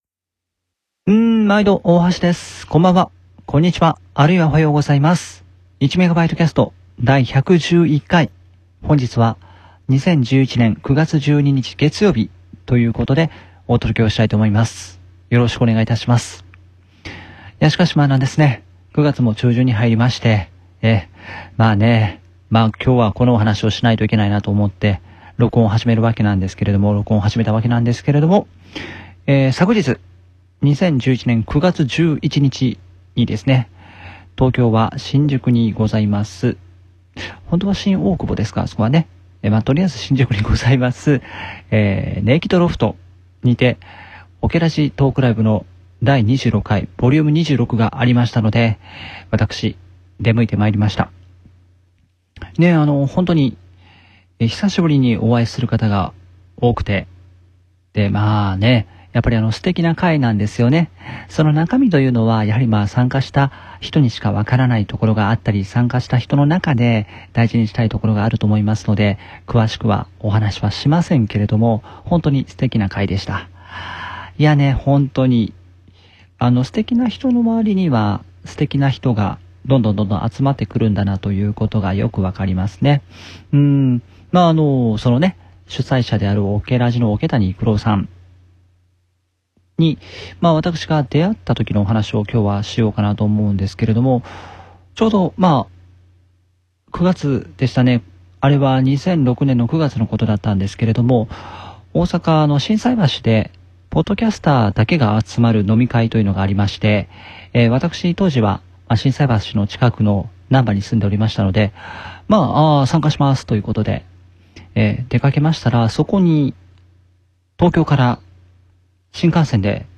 【今回の収録環境：メインPC+オーディオキャプチャ+ダイナミックマイク】